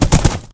horse_gallop4.ogg